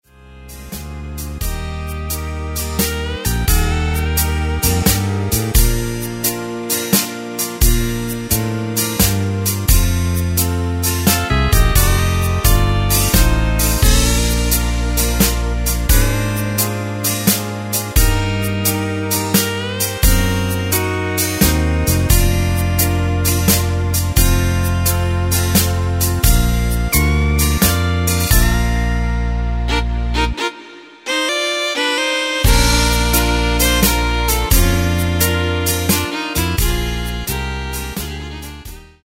Takt:          3/4
Tempo:         87.00
Tonart:            Bb
Slow Waltz!
Playback Demo